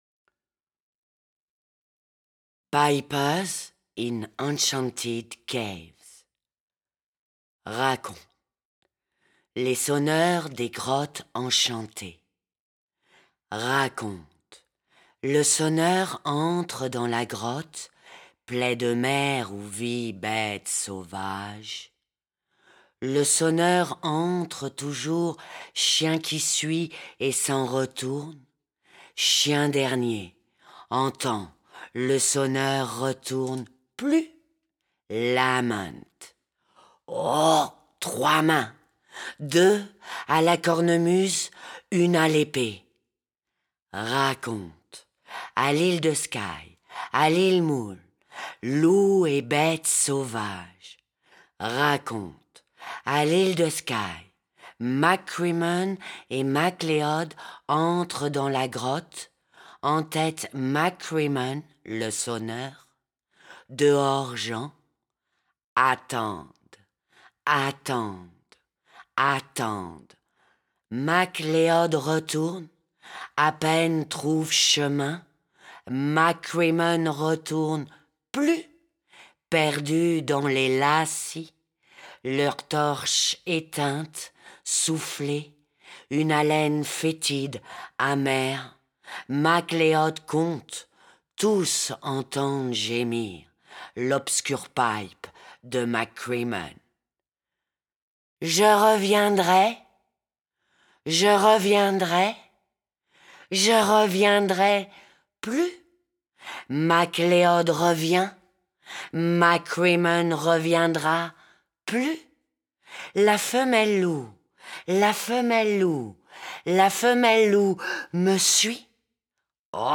lecture
enregistrement & mixage Fulminate Studio